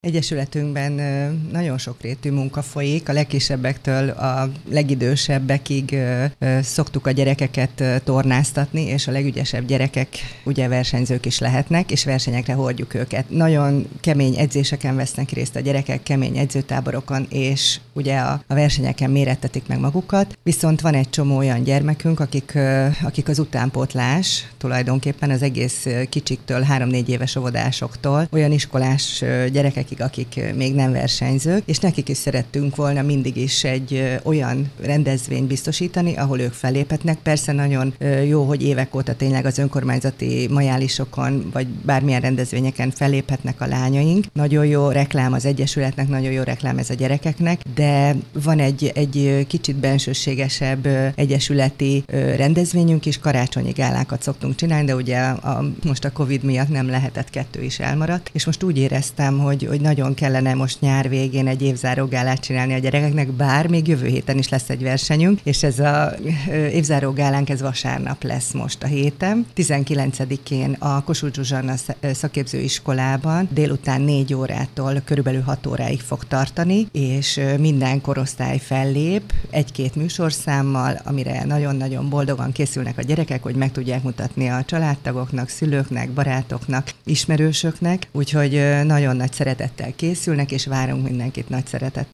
Az iskola esetében pedig régen várt felújítás kezdődik, hiszen az épület modern, a 21. századi követelményeknek megfelelő fűtési rendszert kap. Jóri László polgármestert hallják.